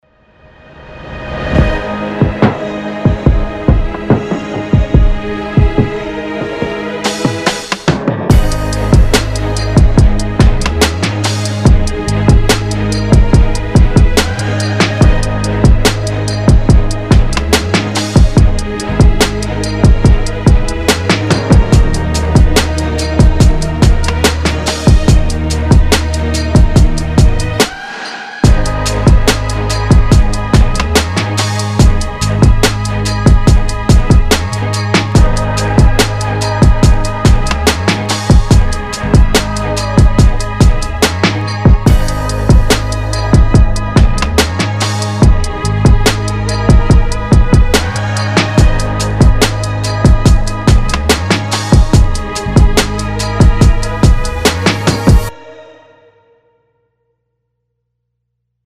通用嘻哈鼓件
适用于现代制作的万能鼓组
经过精良外设处理的有机与合成鼓声